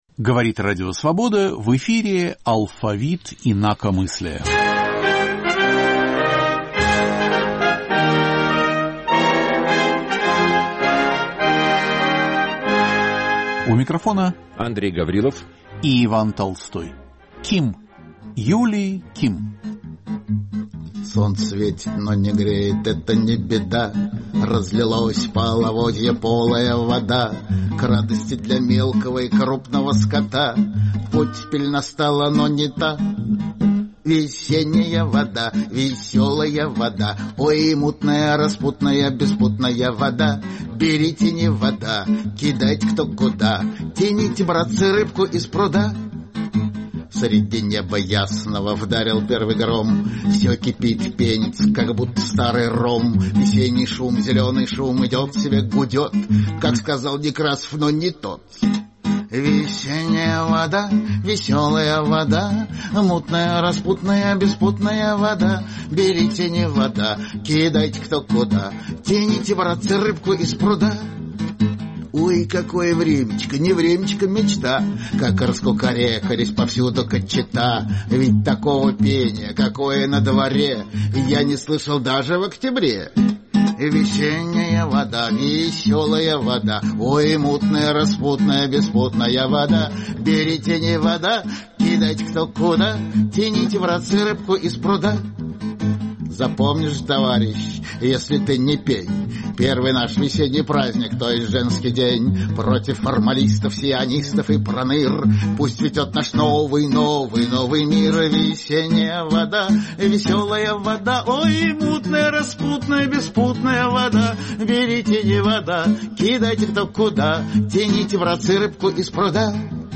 Интервью с Кимом.